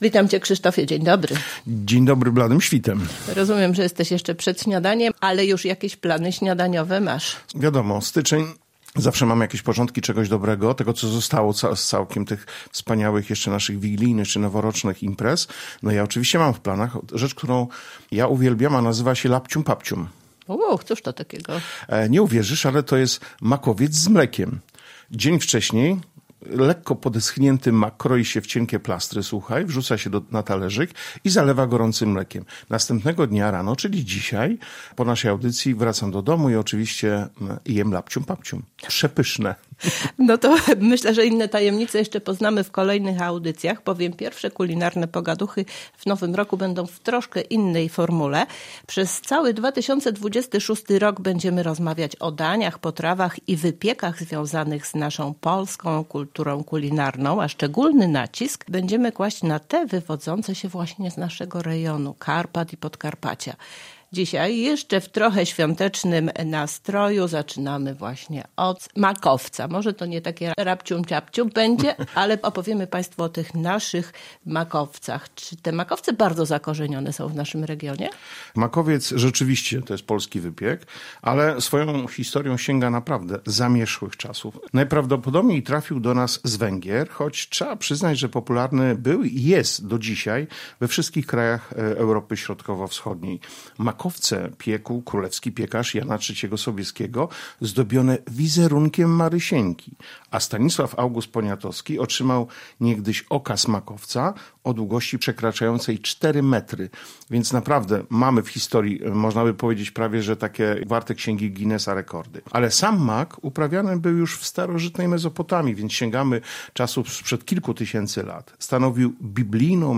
Zapraszamy do posłuchania naszej rozmowy.